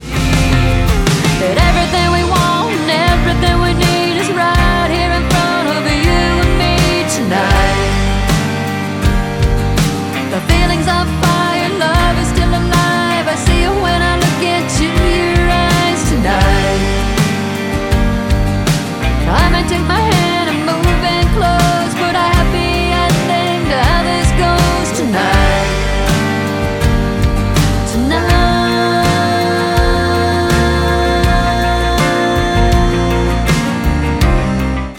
I feel it’s primarily country and americana.
I did more backing vocals